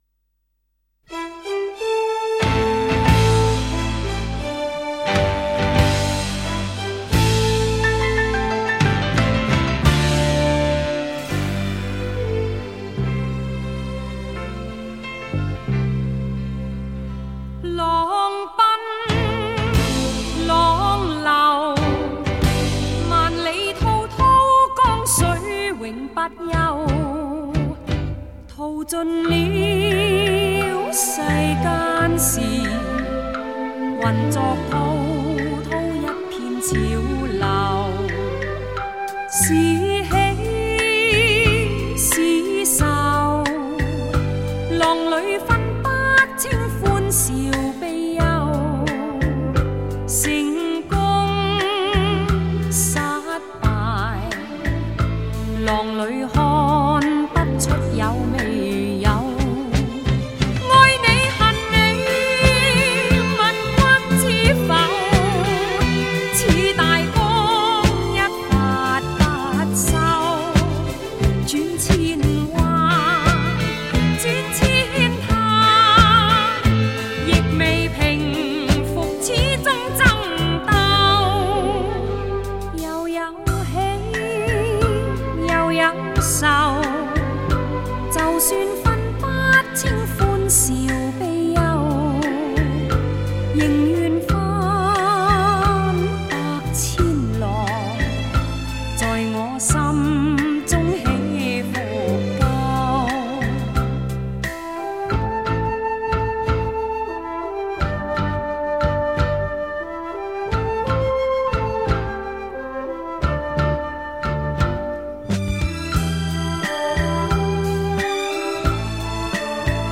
脍炙人口电视主题曲及电影主题曲